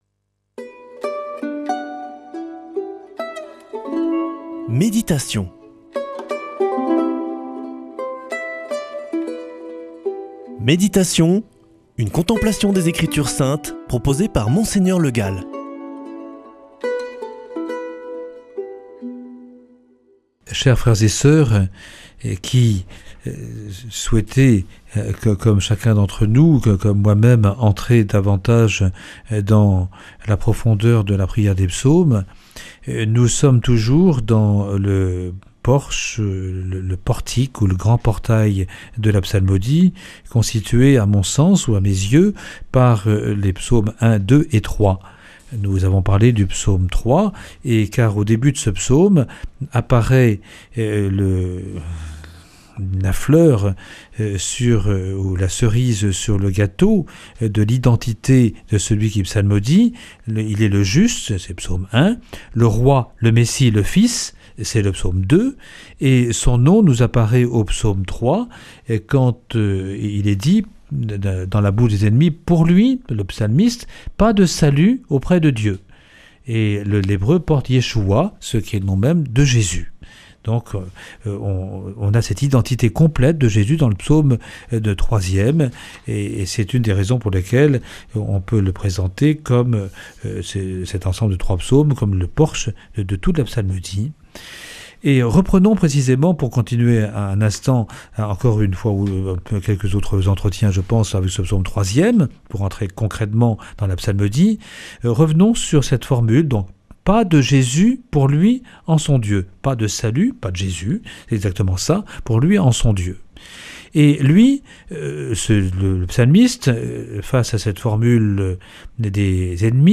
[ Rediffusion ]
Une émission présentée par